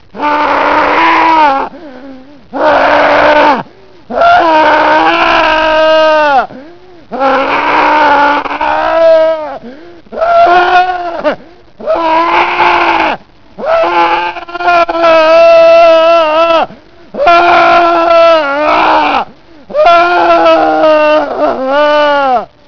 Registrazioni sonore di happening Fluxus
Performer: Dick Higgins